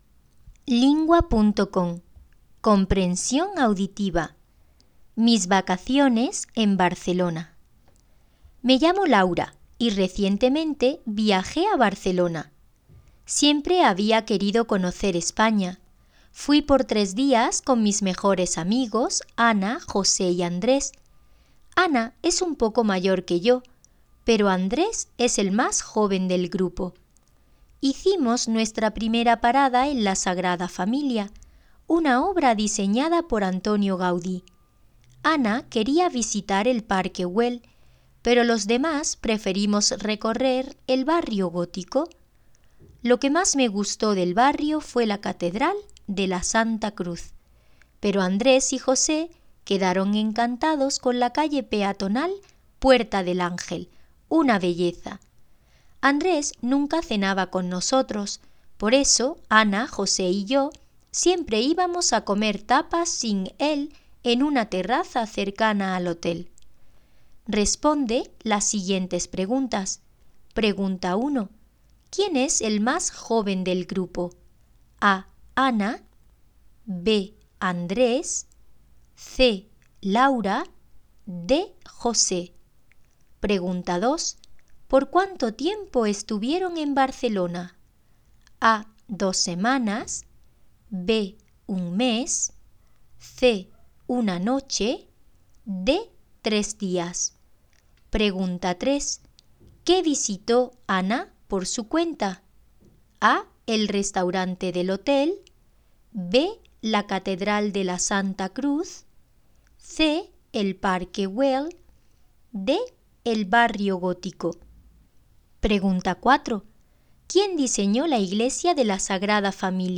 Espagne